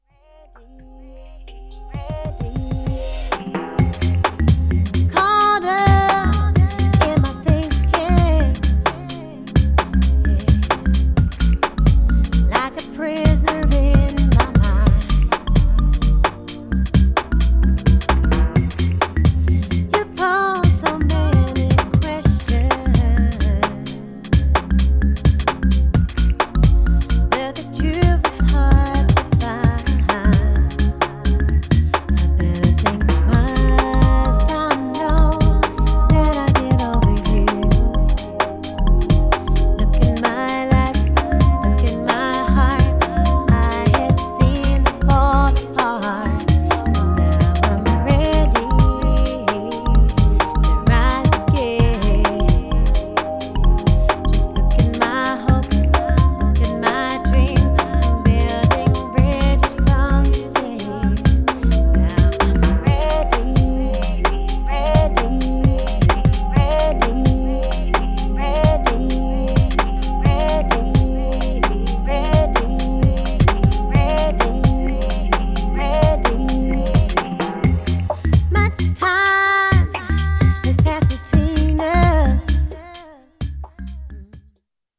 Styl: Progressive, House, Breaks/Breakbeat, Trance